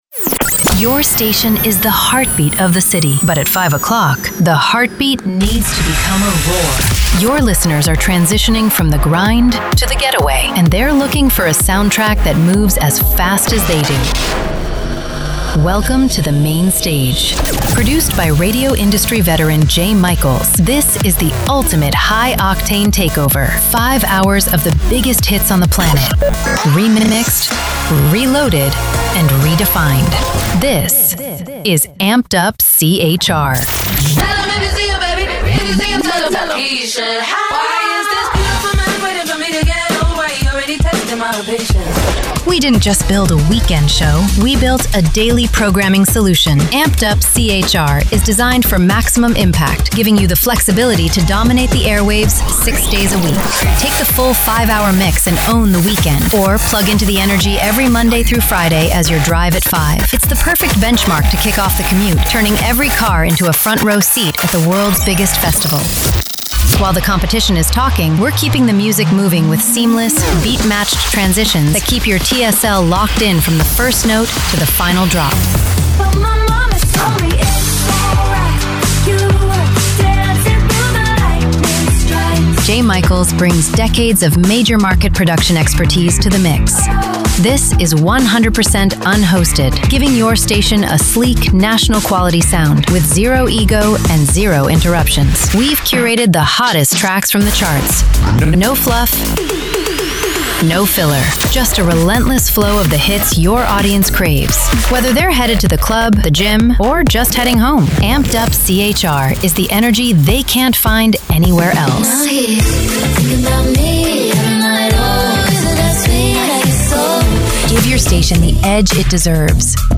Top 40